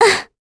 Talisha-Vox_Damage_kr_01.wav